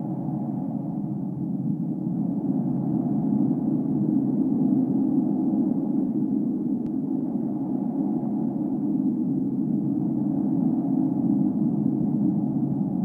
corridor.ogg